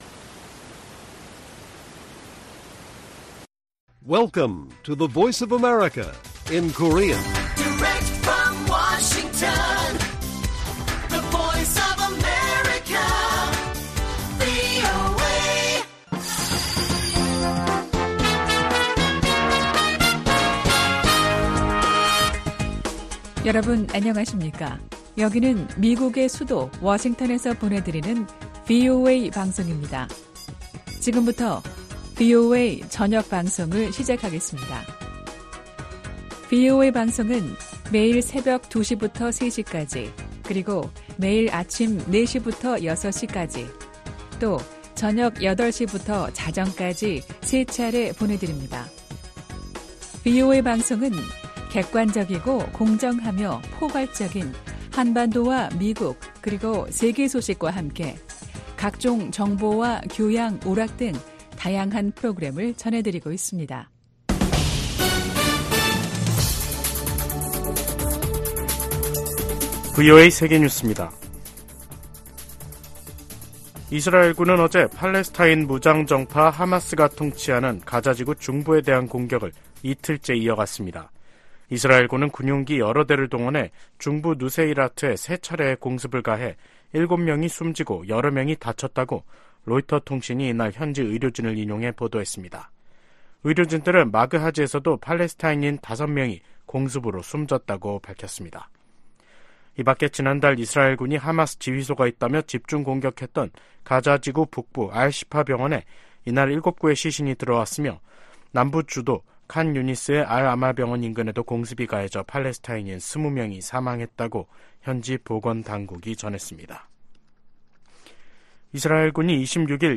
VOA 한국어 간판 뉴스 프로그램 '뉴스 투데이', 2023년 12월 28일 1부 방송입니다. 김정은 북한 국무위원장이 당 전원회의서 전쟁 준비에 박차를 가하는 전투과업을 제시했습니다. 미 국무부가 북한의 중요 정치행사에 등장한 벤츠 행렬에 대해 대북 제재의 운송수단 반입 금지 의무를 상기시켰습니다. 전 주한미군사령관들이 신년 메시지를 통해 미한 연합훈련과 가치 동맹의 확대를 주문했습니다.